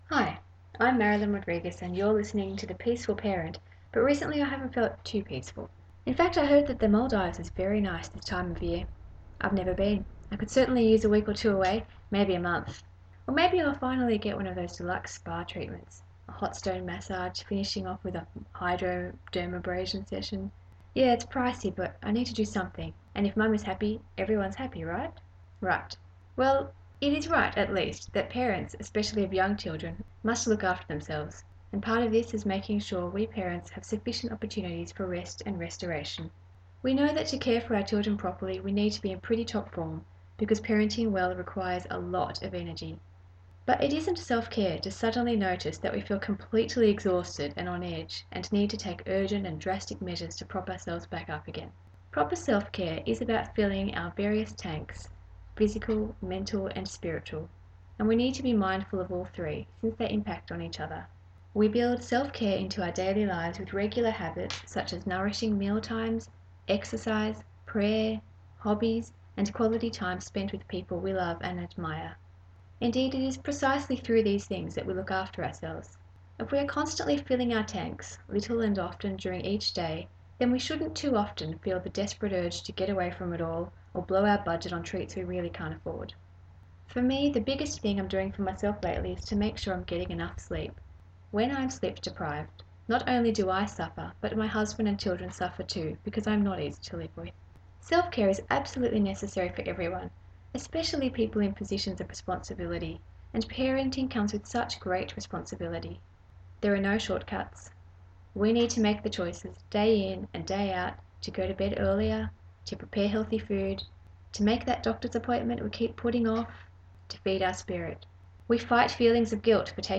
I’ve just recorded a few radio spots for The Journey radio podcast.